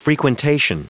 Prononciation du mot frequentation en anglais (fichier audio)
Prononciation du mot : frequentation